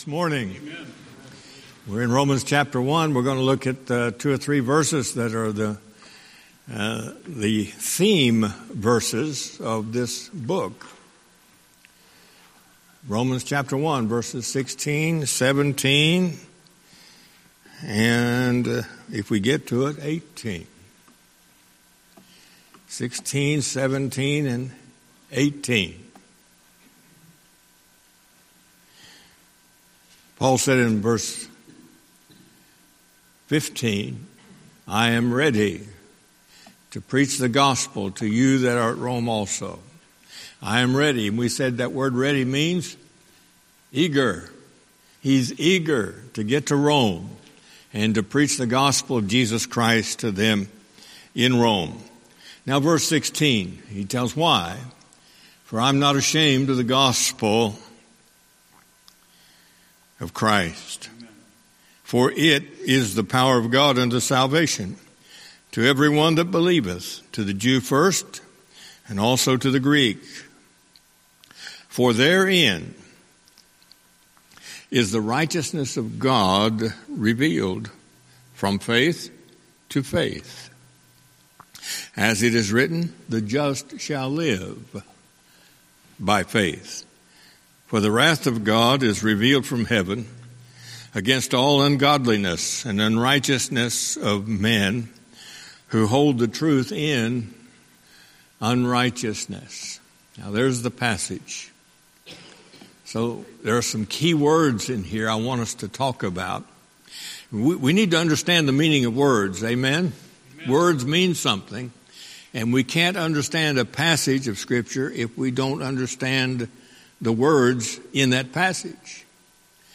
Series: Guest Speaker